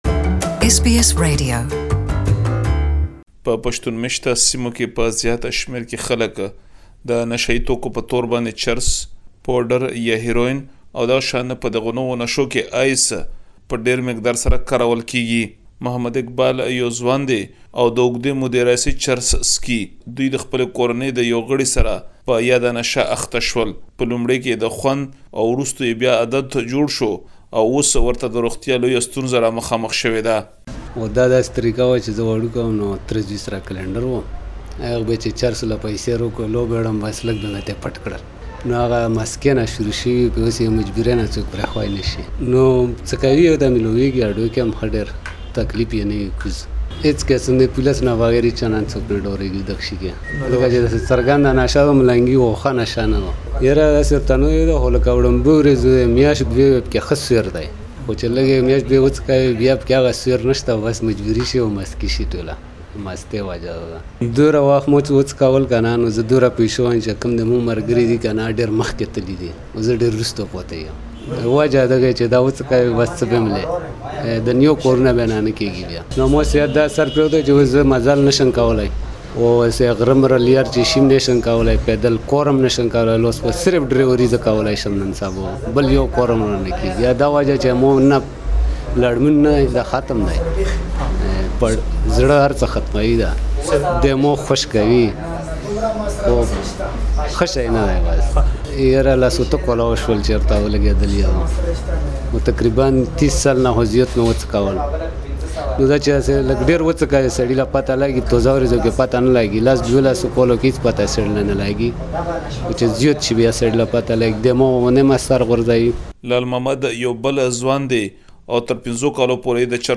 In Pakistan, Pashtun areas are known for their drug addiction and open market. Lack of jobs and easy access to the drugs are seen as the main reasons where Pashtun youth are becoming addicted. Please listen to the full report in Pashto.